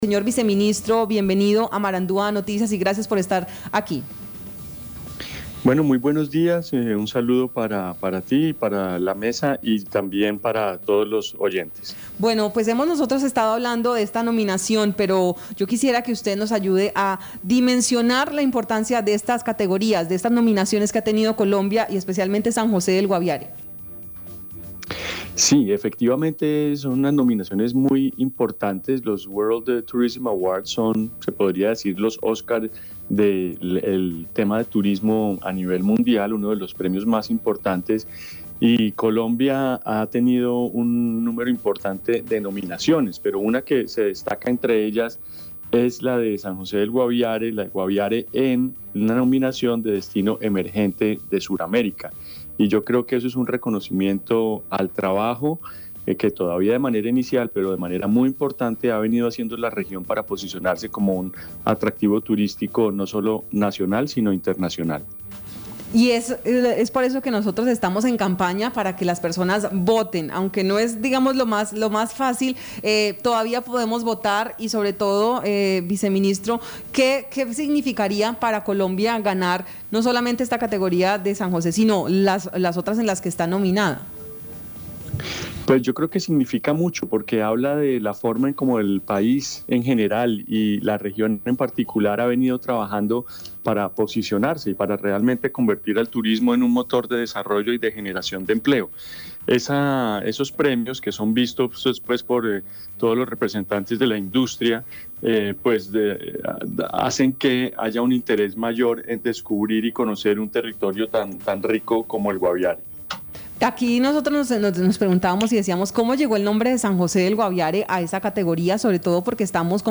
Escuche a Julián Guerrero, viceministro de Turismo.